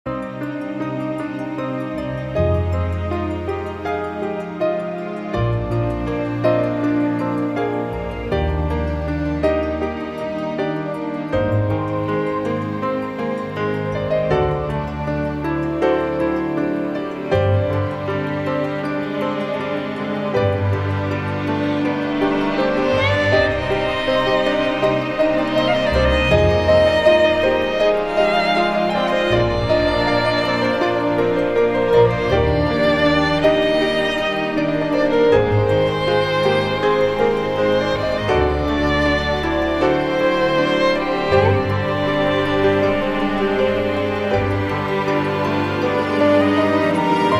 钢琴曲